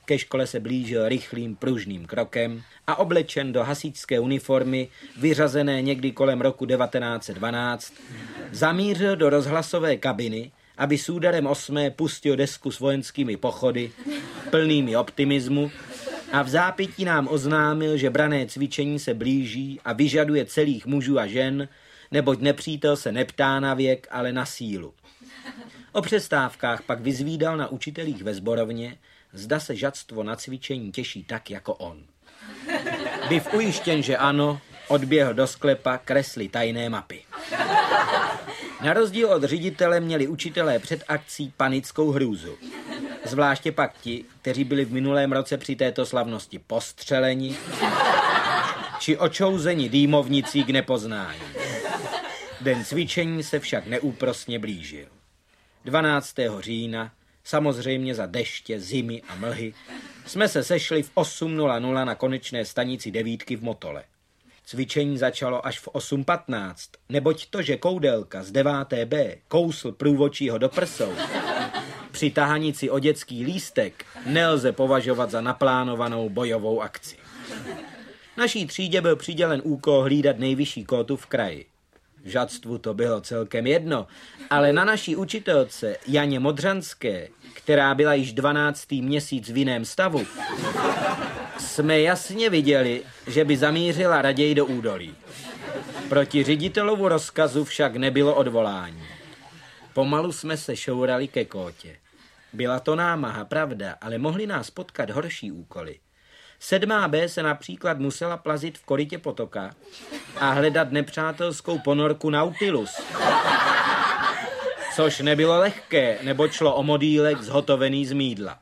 Povídky Šimka a Grossmanna 1 audiokniha
• InterpretMiloslav Šimek